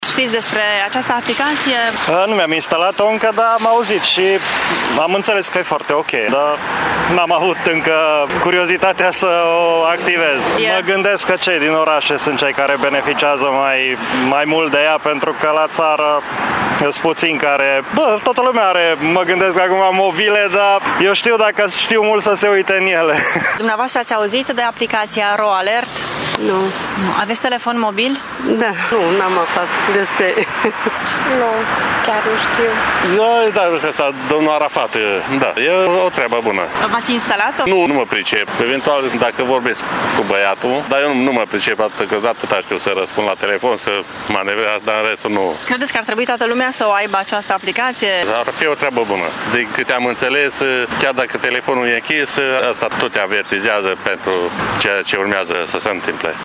Mulți dintre târgumureșeni au auzit despre sistemul de avertizare RO-ALERT, dar nu știu încă ce trebuie să facă pentru a beneficia de el: